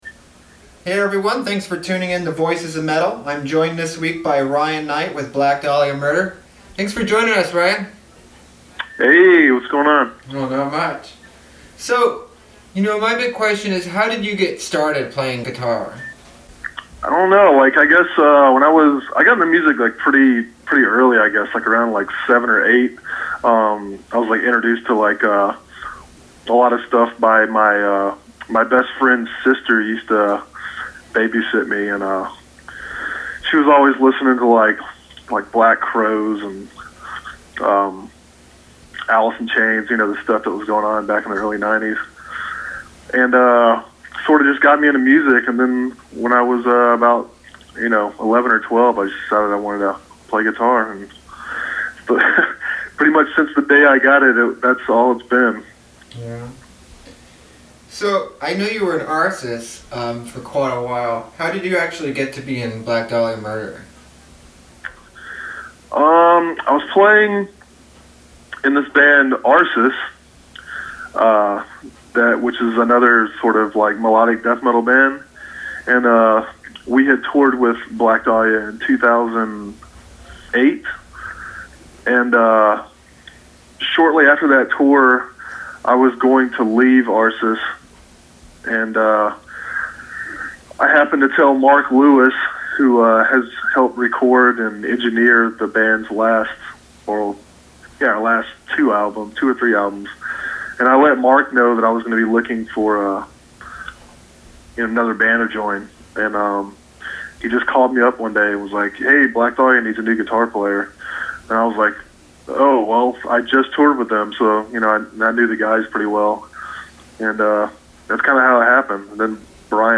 Click here to hear our interview with Ryan Knight of The Black Dahlia Murder